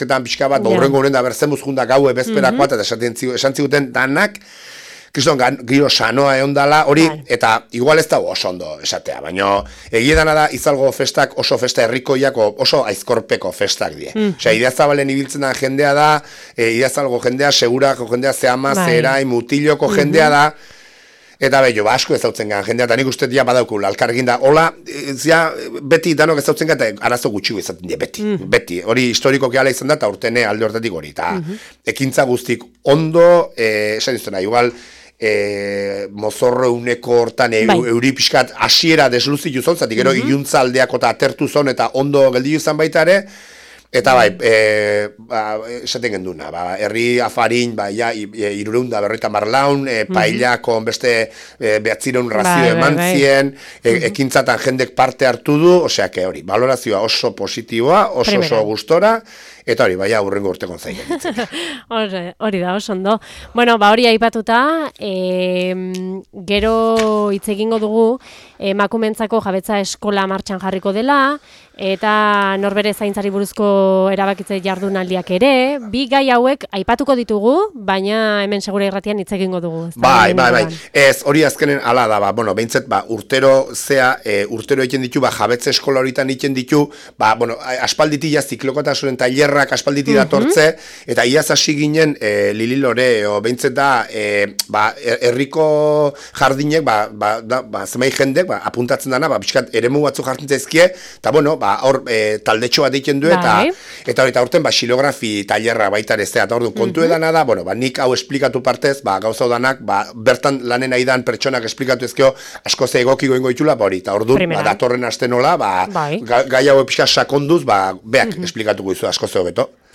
ALKATEEKIN ELKARRIZKETAN – IÑAKI ALBERDI IDIAZABALGO ALKATEA – Segura Irratia